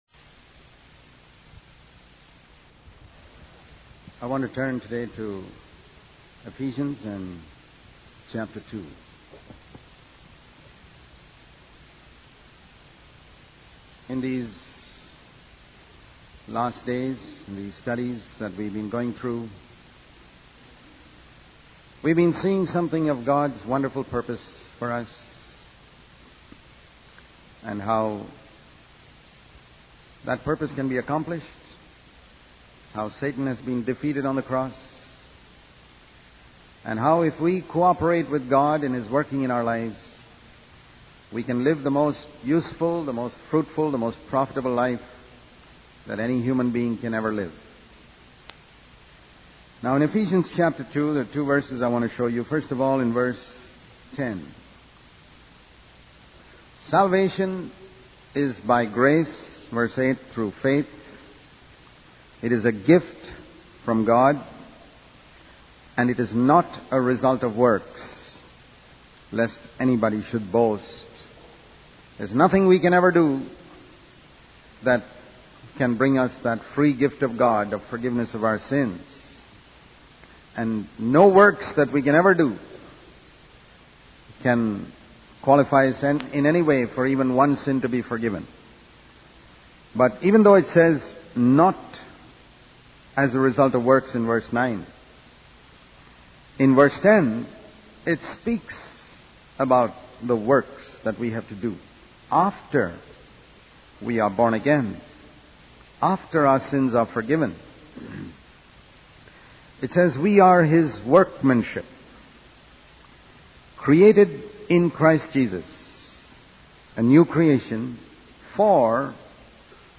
In this sermon, the speaker emphasizes the importance of seeking and following God's plan for our lives. He uses the example of the apostle Paul, who faced numerous challenges and hardships but remained faithful to God's calling.